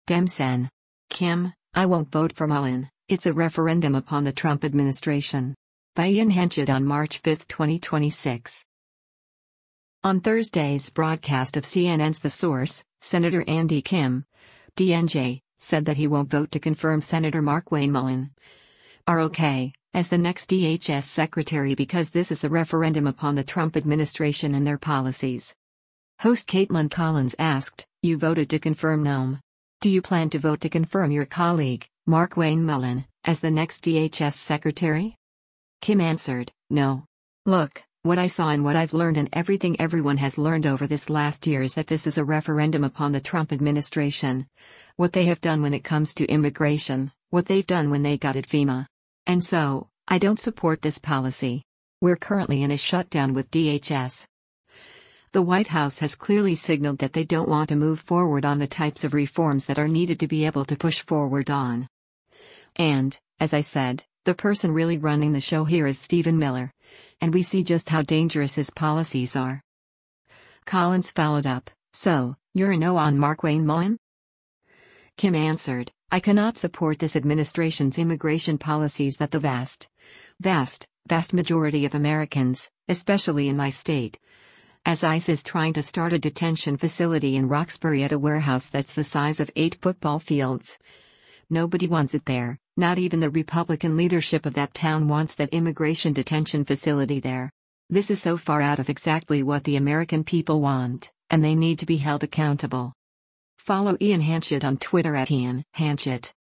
On Thursday’s broadcast of CNN’s “The Source,” Sen. Andy Kim (D-NJ) said that he won’t vote to confirm Sen. Markwayne Mullin (R-OK) as the next DHS secretary because “this is a referendum upon the Trump administration” and their policies.